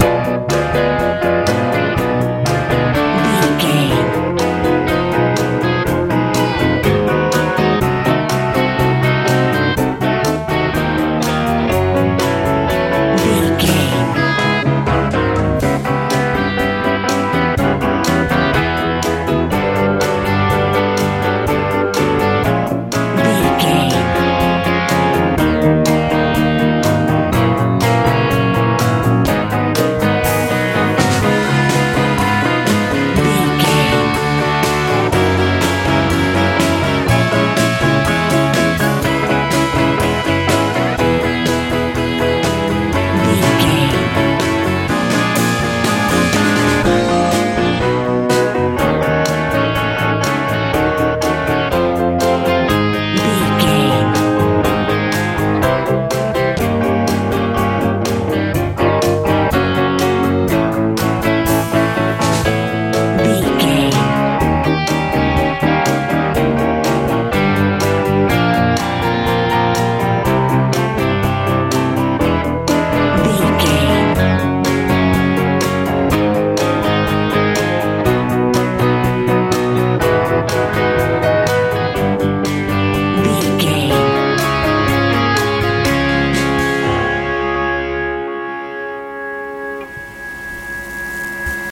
easy rock
Ionian/Major
groovy
funky
acoustic guitar
piano
bass guitar
drums
poignant
lively